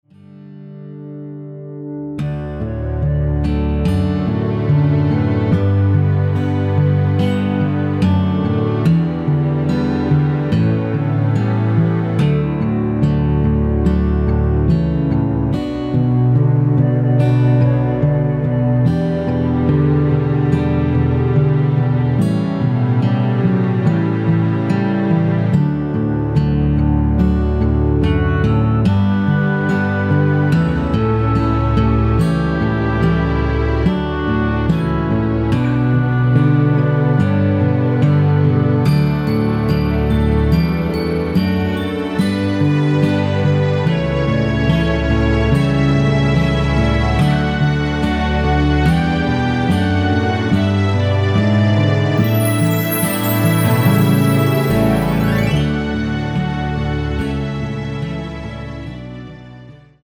멜로디 MR 입니다.
◈ 곡명 옆 (-1)은 반음 내림, (+1)은 반음 올림 입니다.
앞부분30초, 뒷부분30초씩 편집해서 올려 드리고 있습니다.
중간에 음이 끈어지고 다시 나오는 이유는